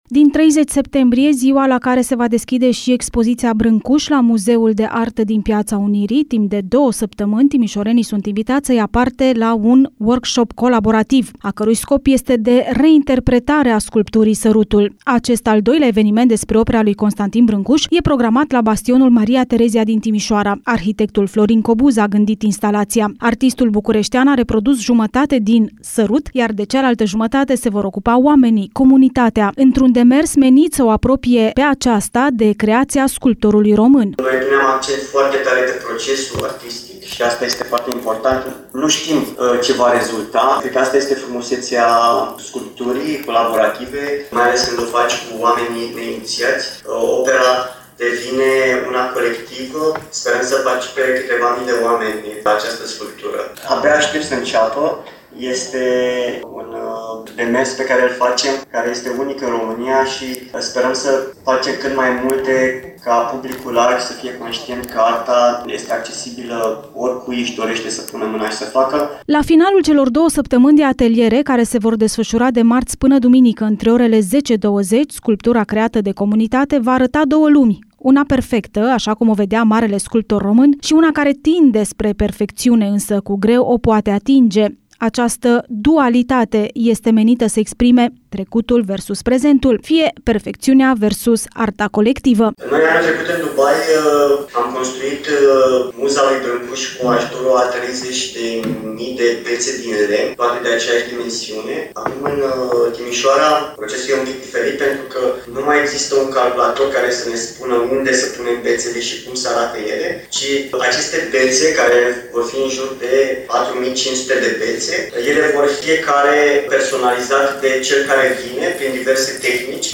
Consiliul Județean Timiș își propune să „traducă” opera marelui sculptor și pentru cei mai puțini familiarizați cu simbolistica ei, spune președintele Alin Nica.